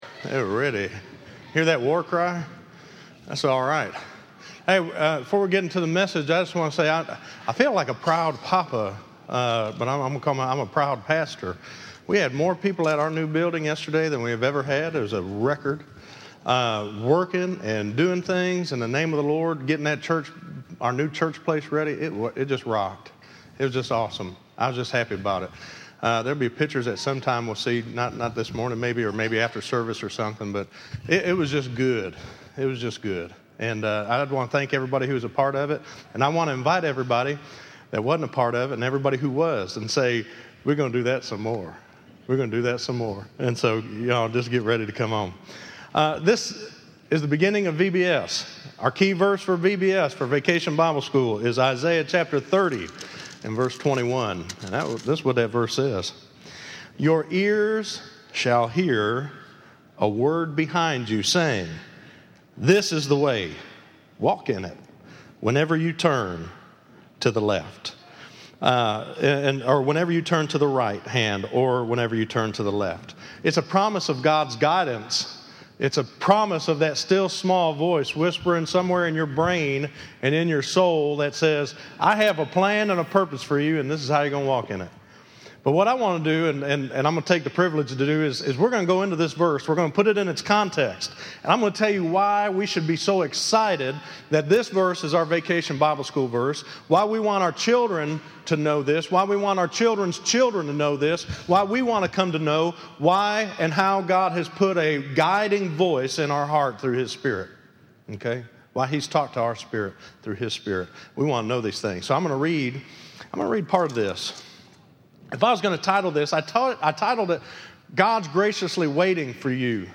Listen to God Is Graciously Waiting For You - 07_19_15_Sermon.mp3